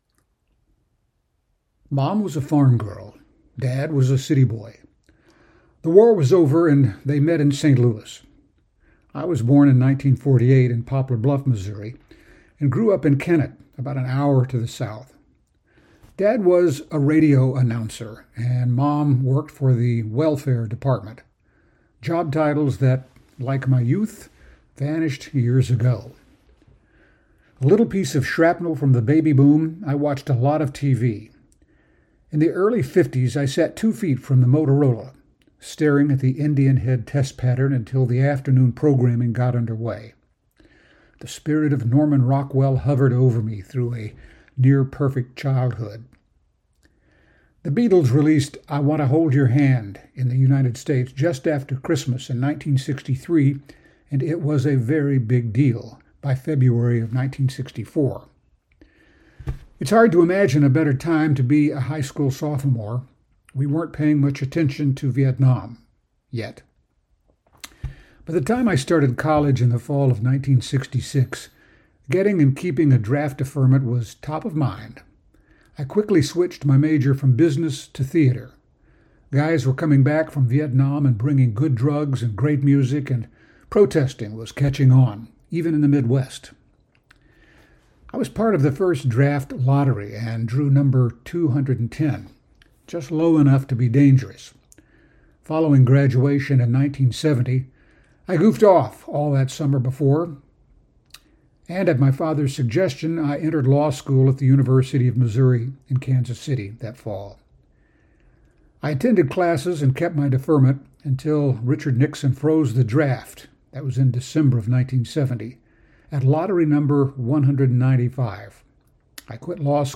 I uploaded a recording of me reading that same bio.
The the second audio is a recording of my voice.